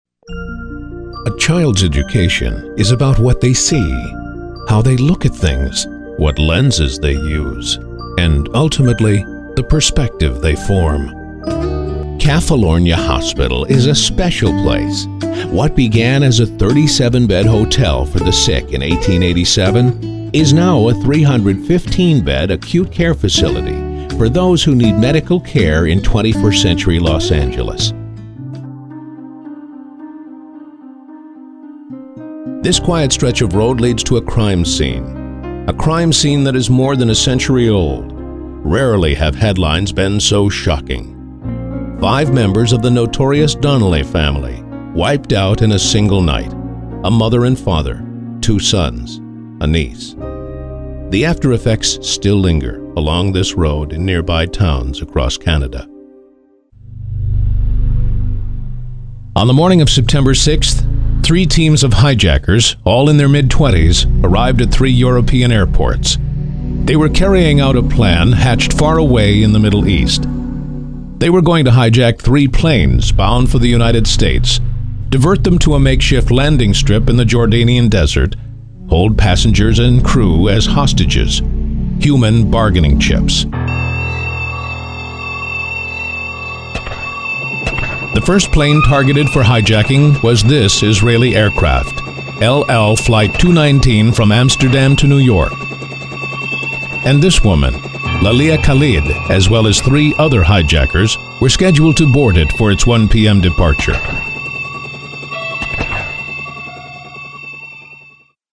Bright and friendly, or deliberate and commanding.
Sprechprobe: Industrie (Muttersprache):